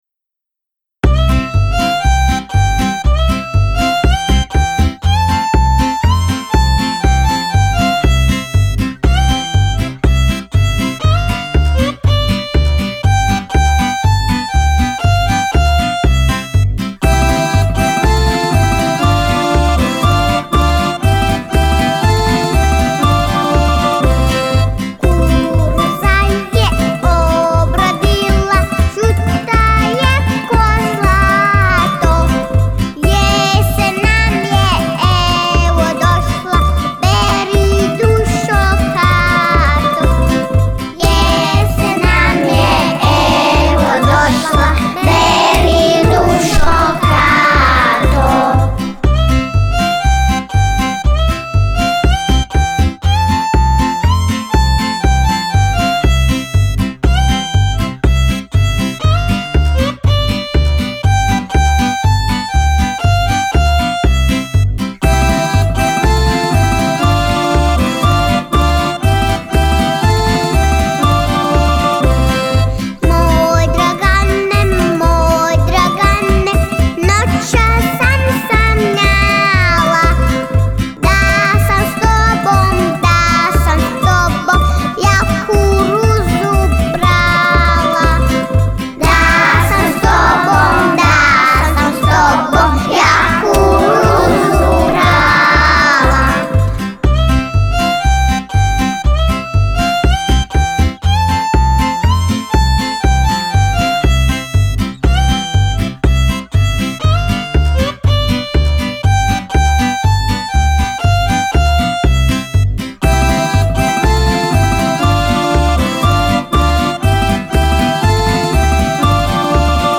Ch 4  * MELODY GUIDE